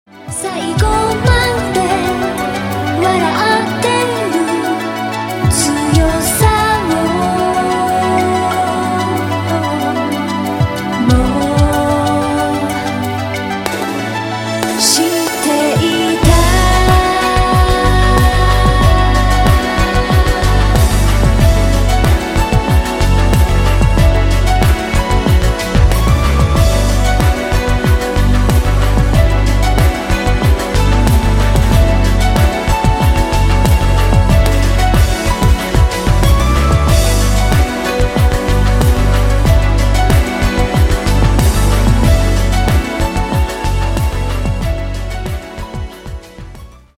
• Качество: 192, Stereo
женский вокал
спокойные
японские